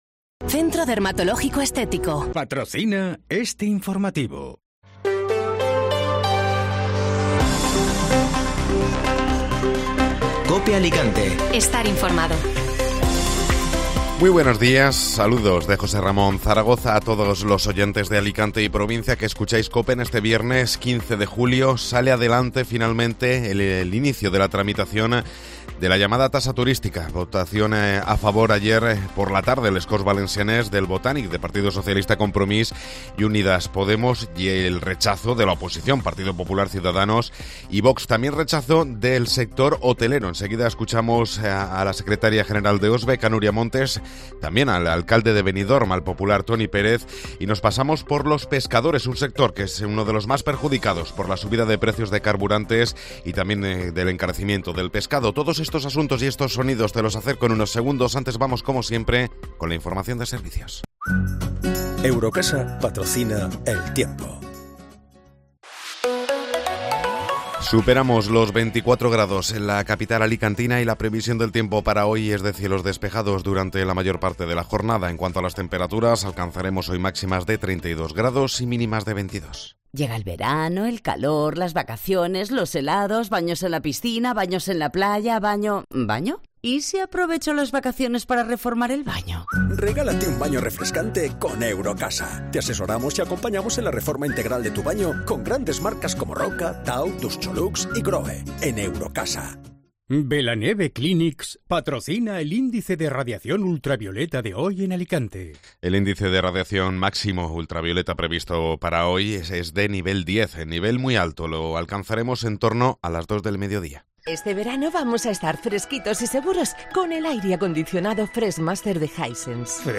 Informativo Matinal (Viernes 15 de Julio)